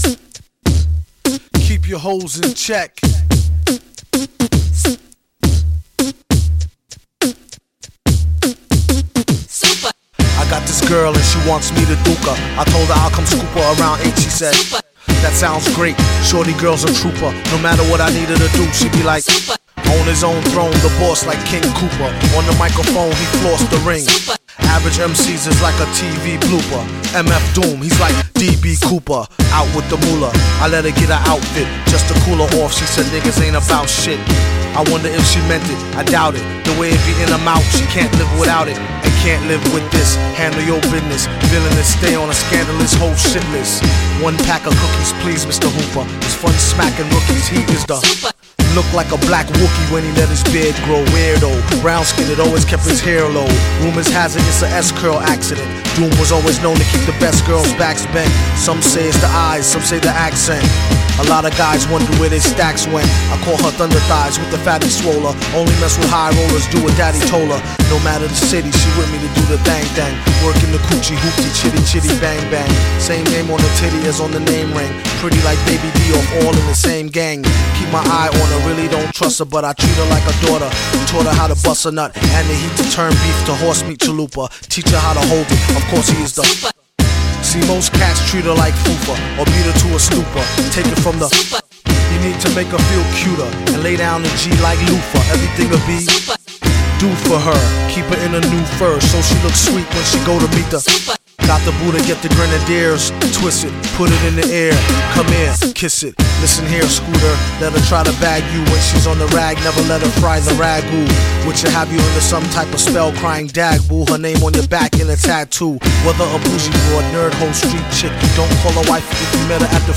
classic beat series